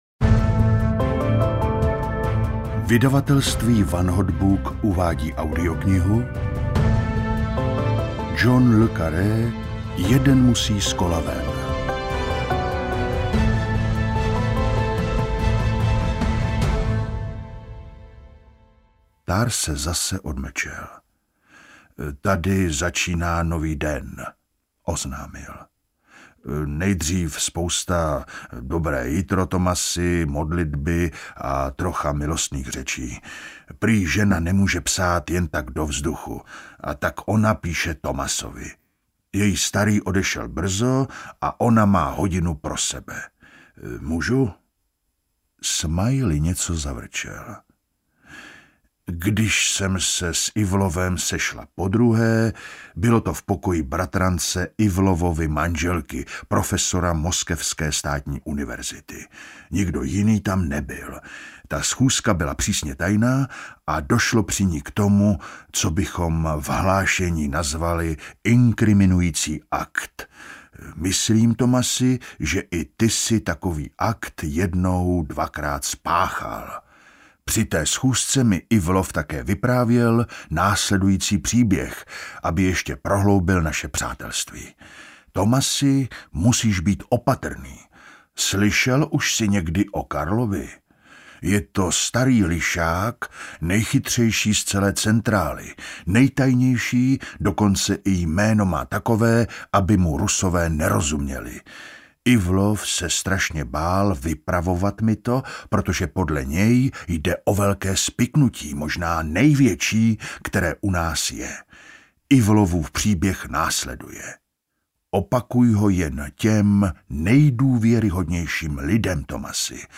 Jeden musí z kola ven audiokniha
Ukázka z knihy